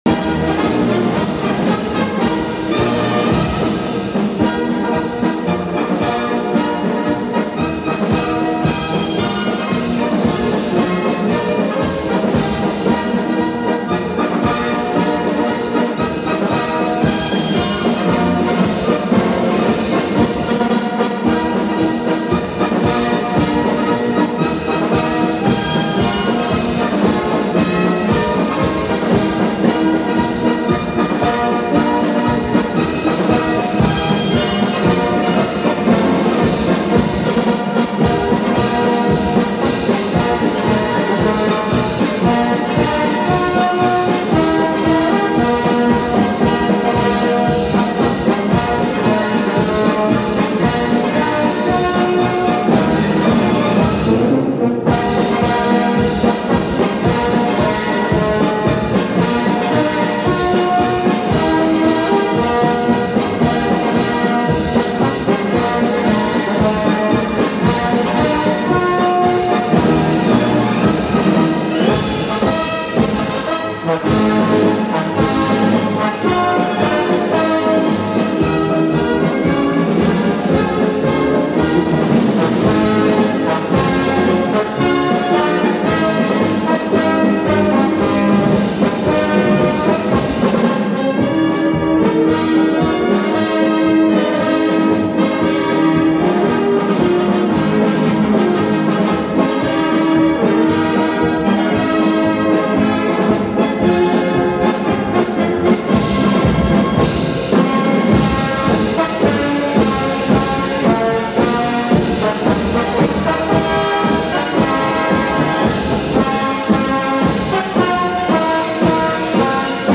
I've been really enjoying this march that I recently discovered.